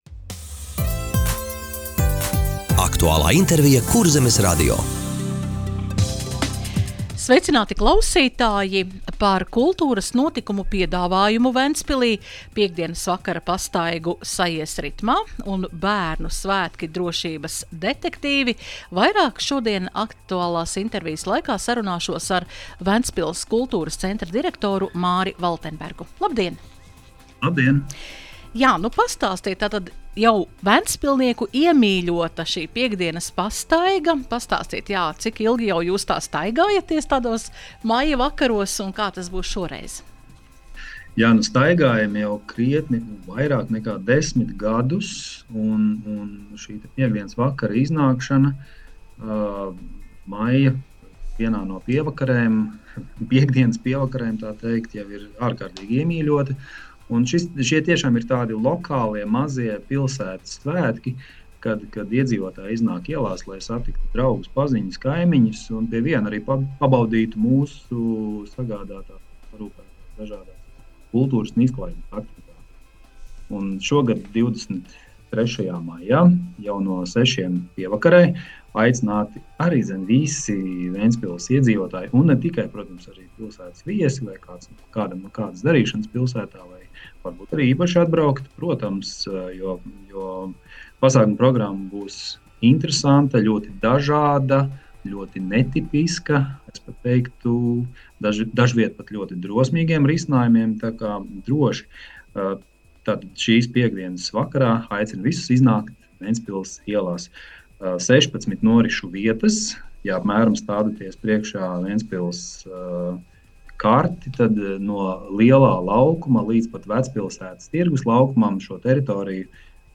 Radio saruna Kultūras notikumu piedāvājums Ventspilī